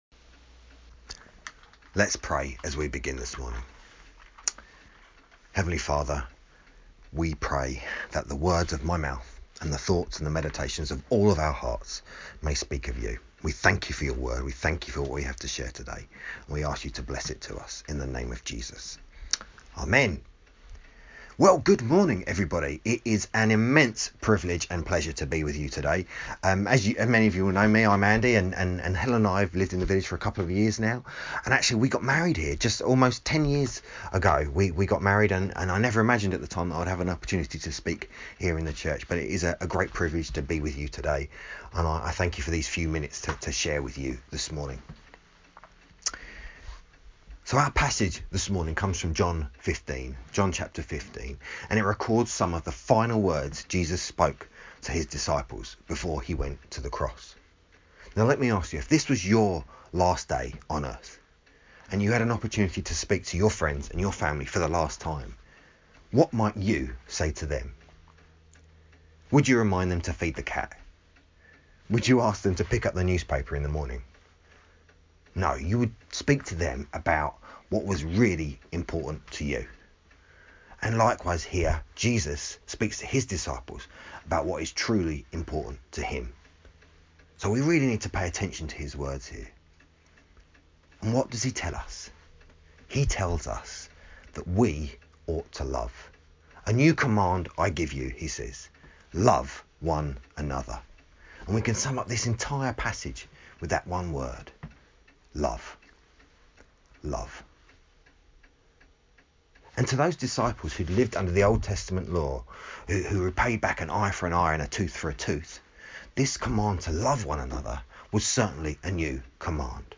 The Command to Love (a short talk on John 15:9-17)